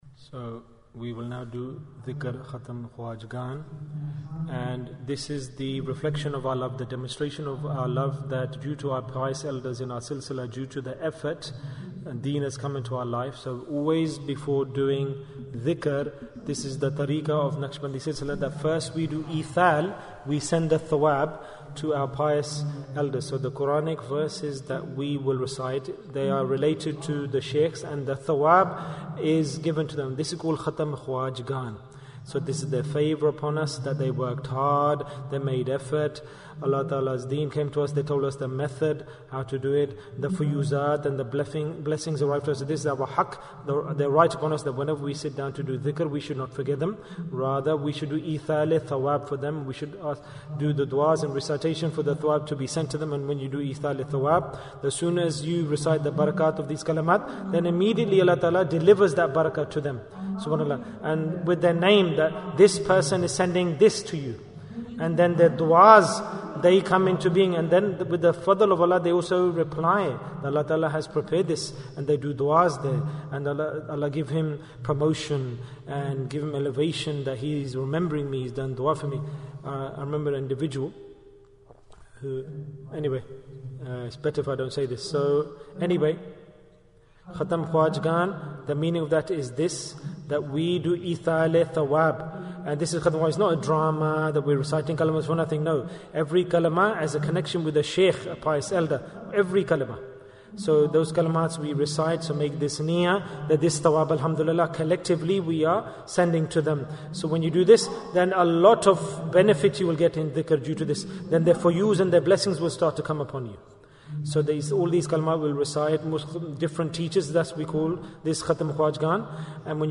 Bayan, 7 minutes